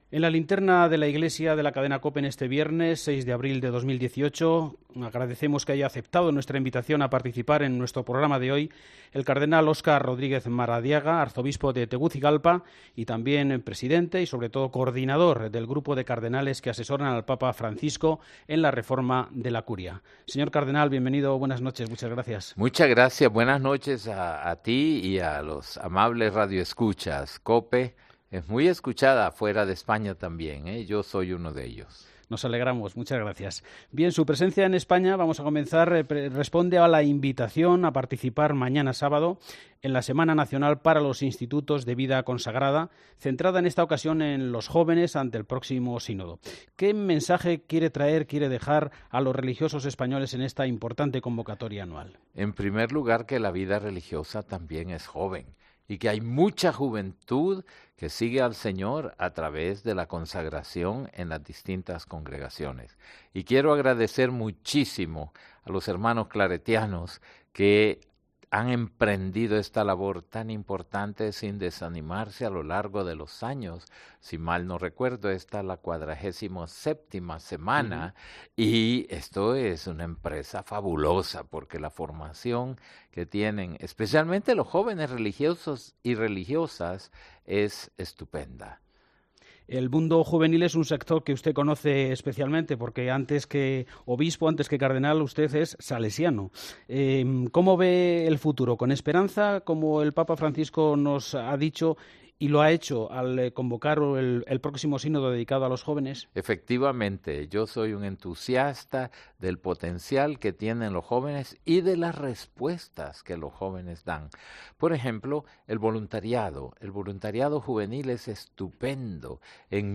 Entrevista al Cardenal Óscar Rodríguez Maradiaga